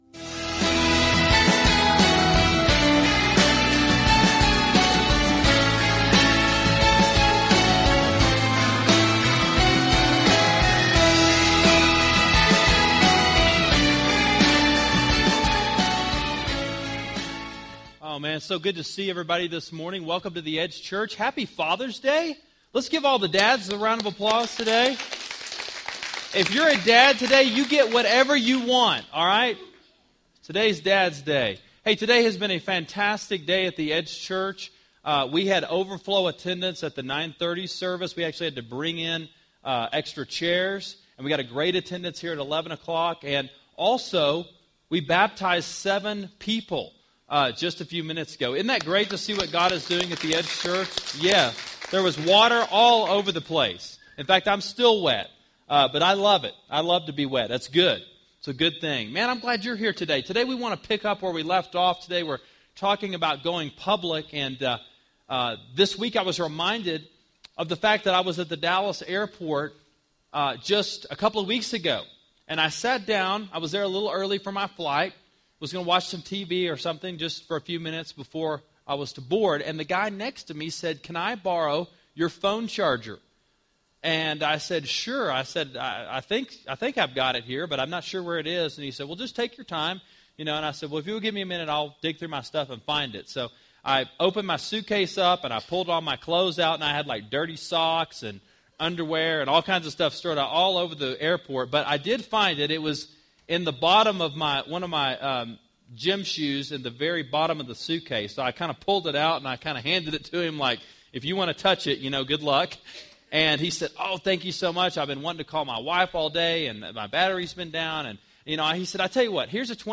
Share the Sermon: